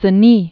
(sə-nē), Mont